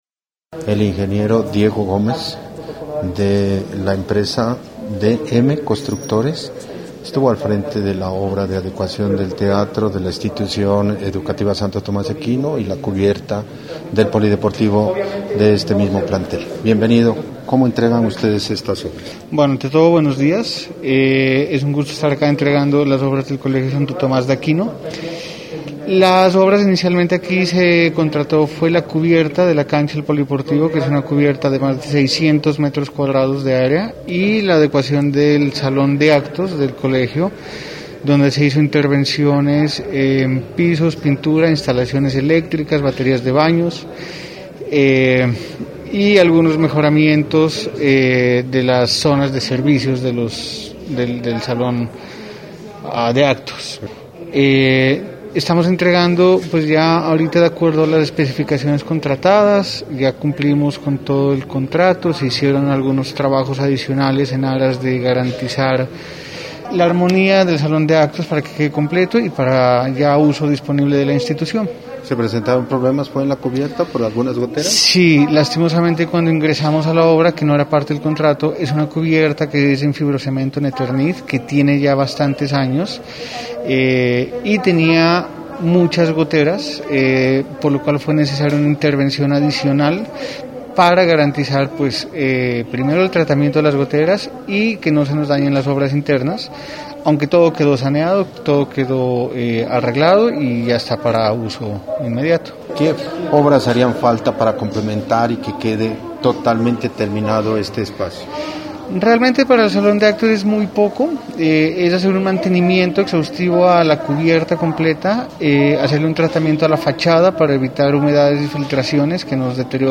Los ingenieros responsables del proyecto de adecuación del salón de actos y construcción de la cubierta del polideportivo de la Institución educativa Tomás de Aquino de Sandoná hicieron entrega de las obras este viernes en horas de la mañana.